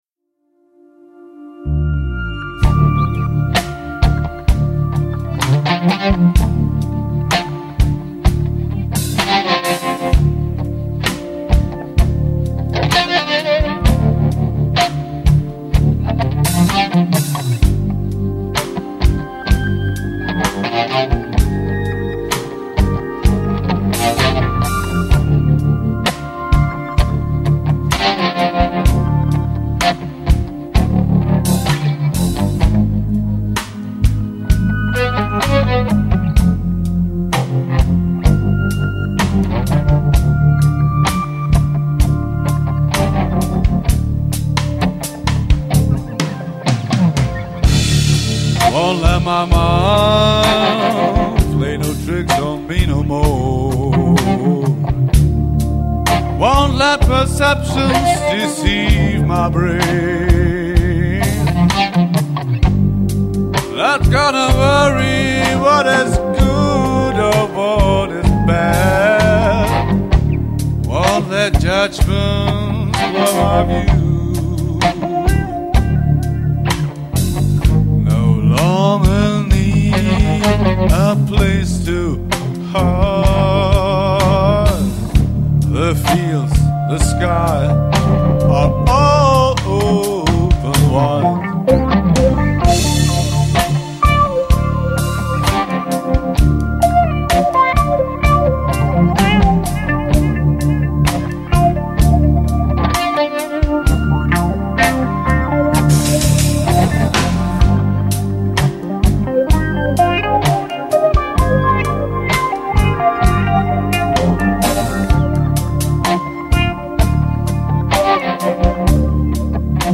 voice
guitars, samples, percussion, electronica
bass guitar
drums